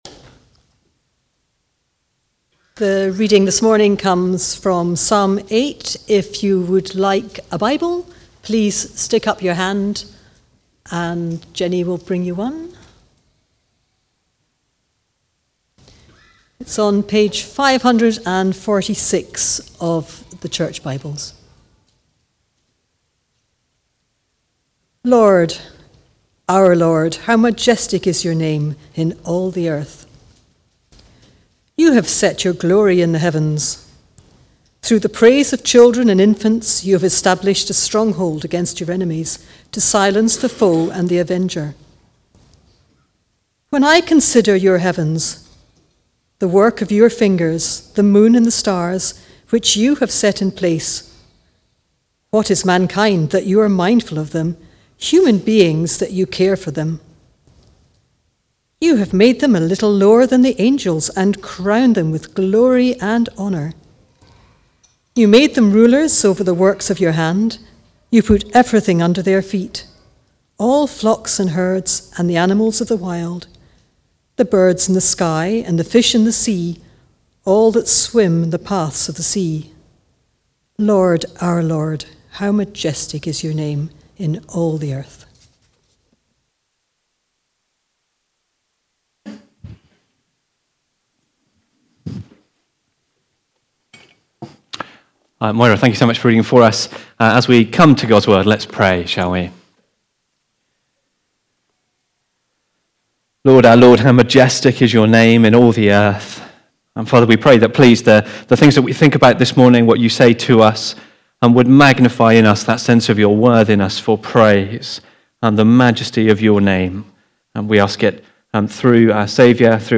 Preaching
Recorded at Woodstock Road Baptist Church on 15 December 2024.